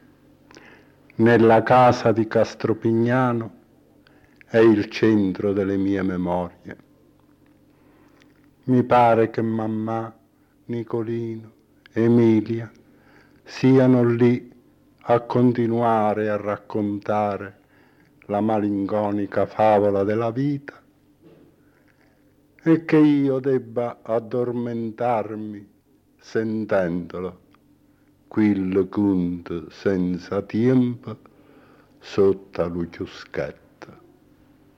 Messaggio registrato su nastro nel luglio del 1954)